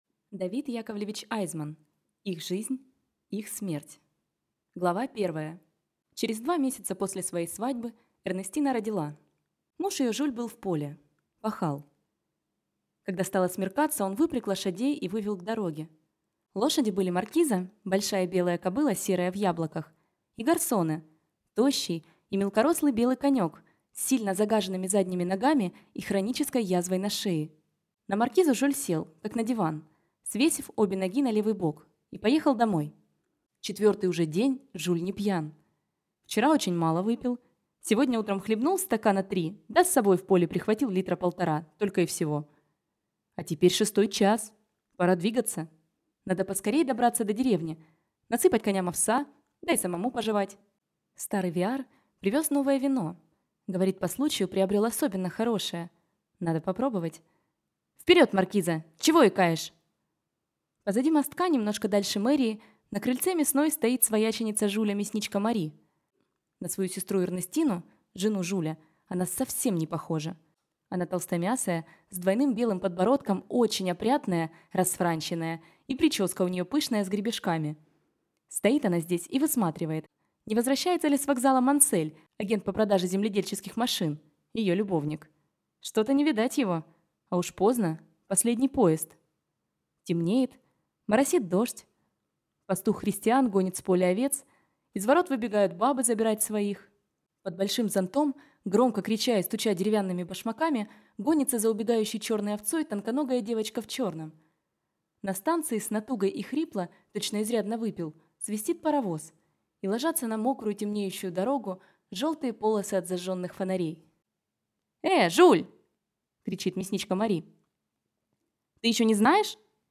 Аудиокнига Их жизнь, их смерть | Библиотека аудиокниг